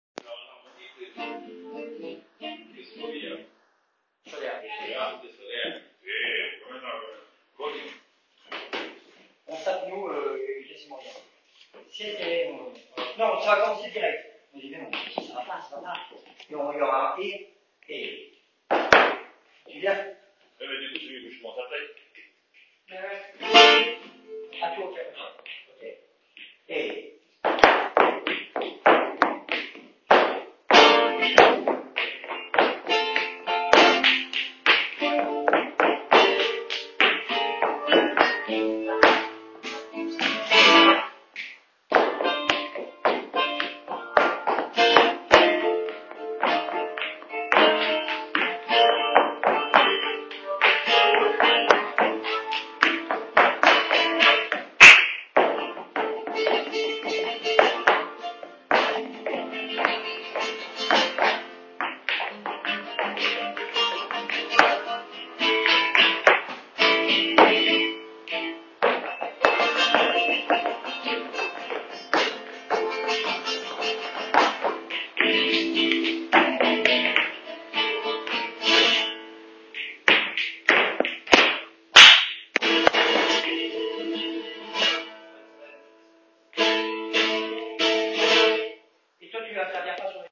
2_29 – mélodie escobilla de solea à la guitare (audio):